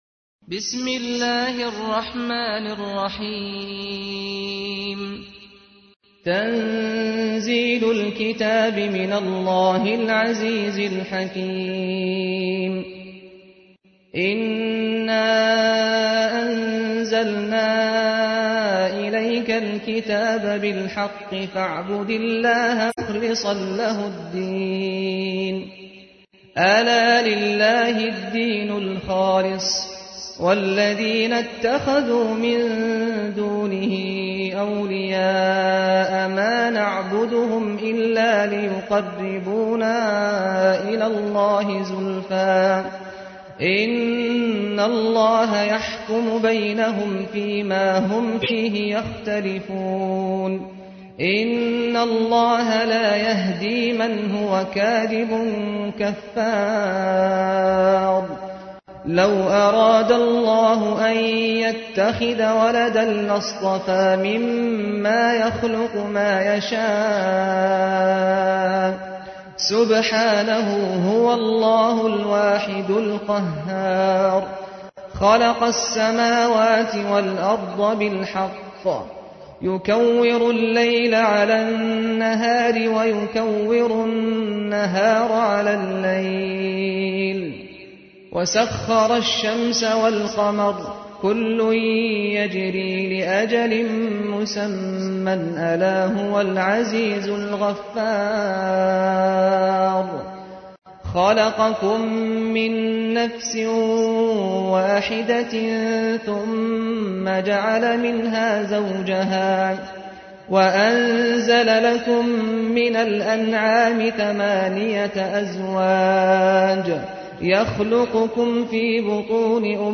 تحميل : 39. سورة الزمر / القارئ سعد الغامدي / القرآن الكريم / موقع يا حسين